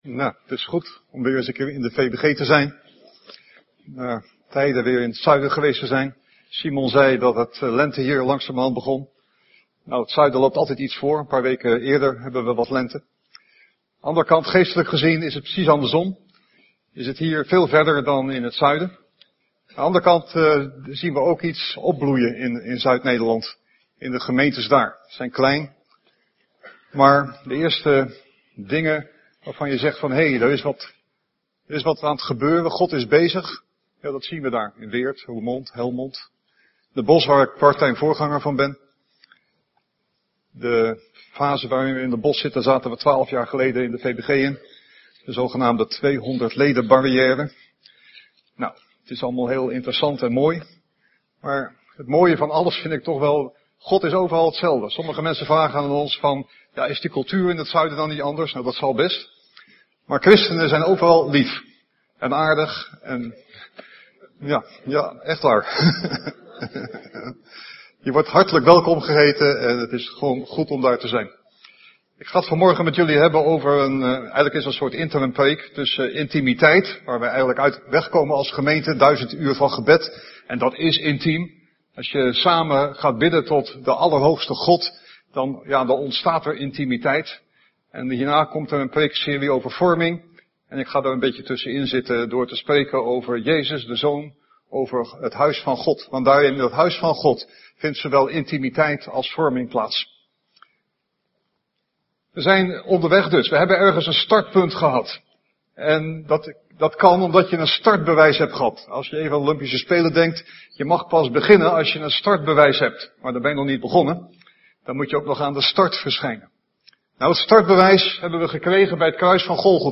Om ook de woordverkondiging van de zondagse diensten te kunnen beluisteren op welk moment en waar je maar wilt, worden de preken ook als audio-uitzending gedeeld.